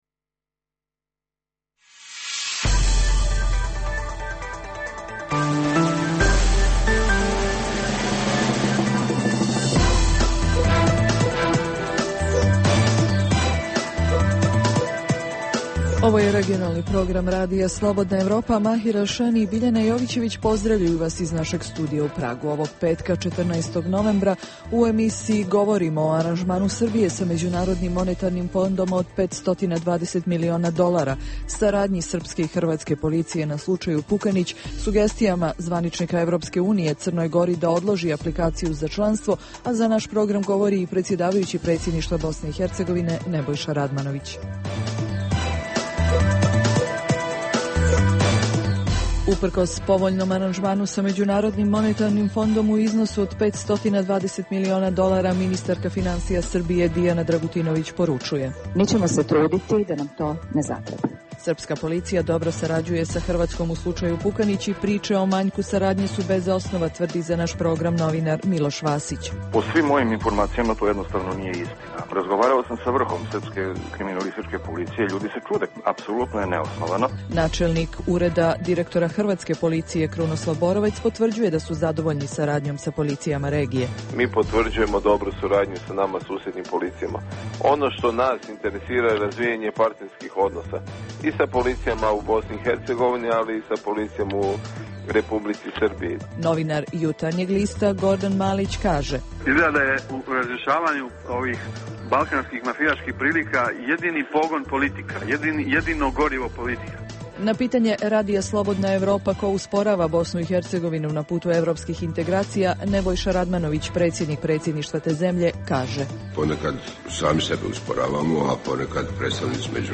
Danas govorimo o aranžmanu Srbije sa MMF od 520 miliona dolara, te o saradnji srpske i hrvatske policije na slučaju Pukanić. Za naš program govori i predsjedavajući Predsjedništva BiH Nebojša Radmanović.